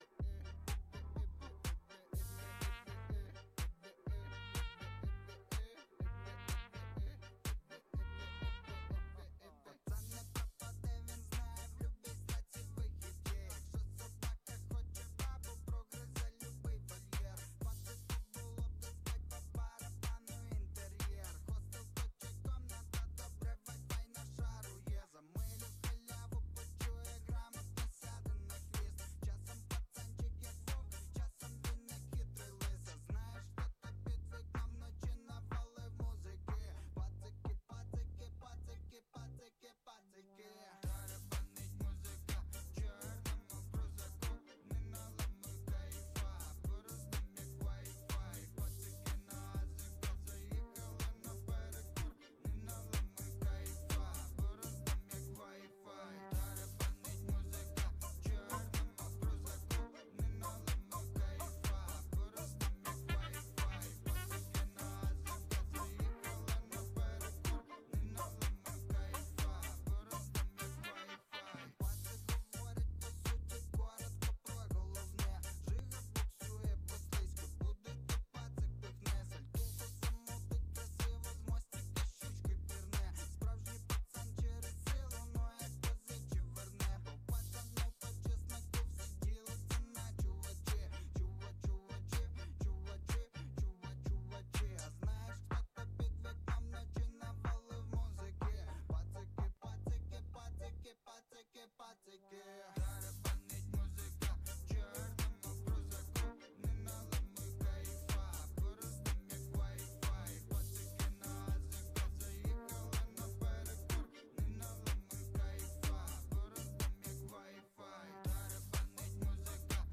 Постоянная ссылка URL (SEO) (SEO) Текущее время (SEO) Категория: Караоке вечори в Одесі Описание: Караоке Одеса караоке-бар "PRINCE"!